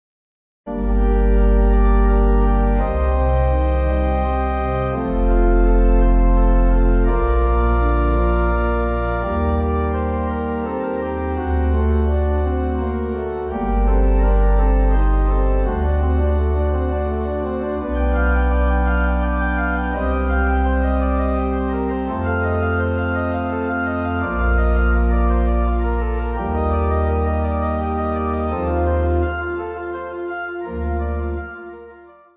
Organ
Easy Listening   Gm